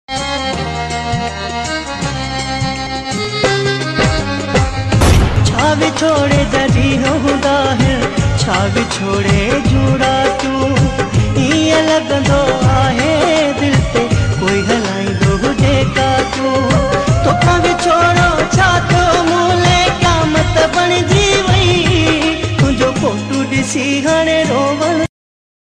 sad viral song